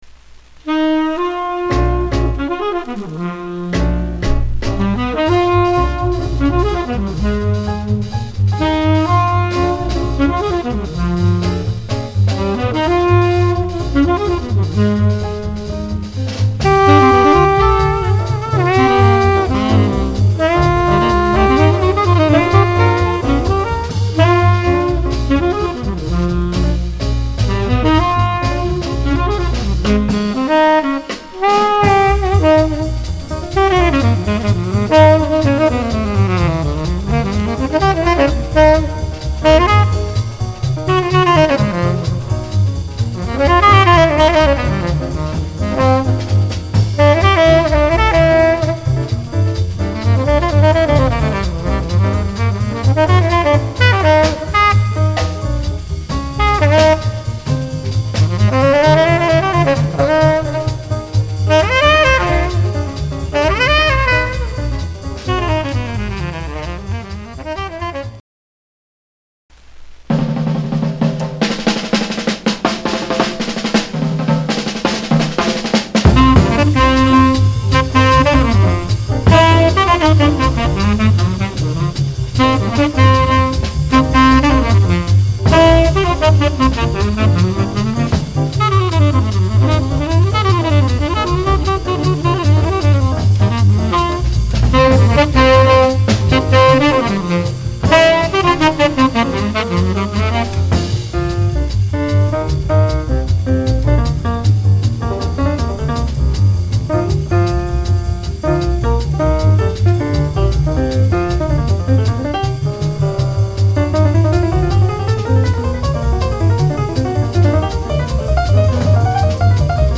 discription:Mono両溝ターコイズ・カゼヒキあり
Format：LP
ウエストコーストの人気テナー
軽いカゼヒキがありますが、モノラルカートリッジで軽減できますので試聴でお試しください。